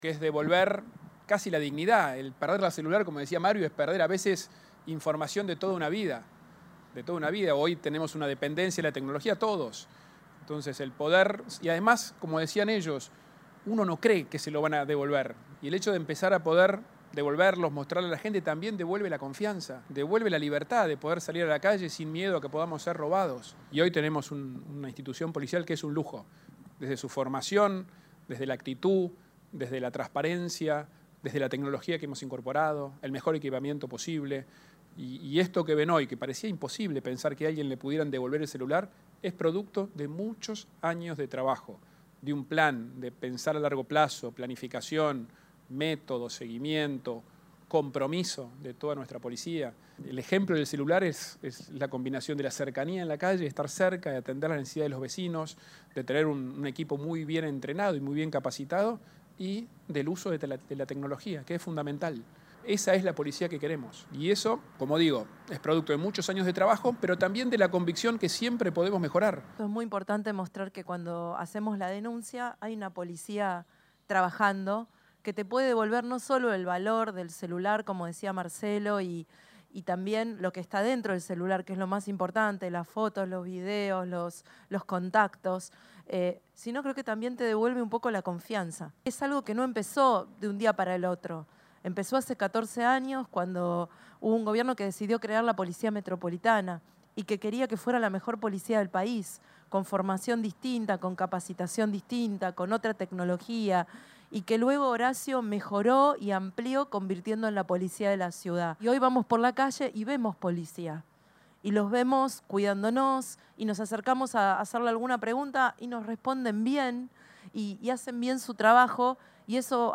El Jefe de Gobierno porteño encabezó una nueva devolución de teléfonos celulares a sus legítimos dueños, luego de ser recuperados del delito por medio de procedimientos realizados por la Policía de la Ciudad y la Agencia Gubernamental de Control.